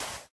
sand2.ogg